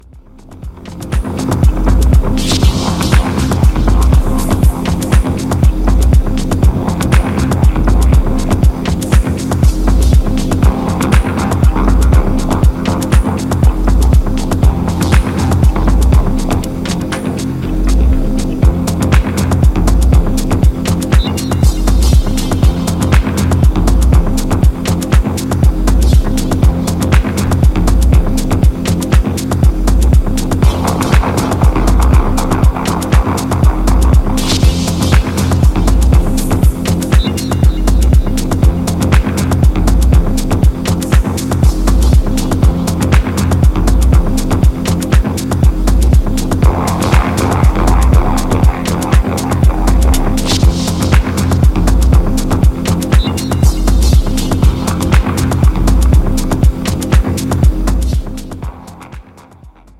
オーガニックな質感と緻密な音響設計が最高なダブ・テクノ作品。
New Release Dub Techno Techno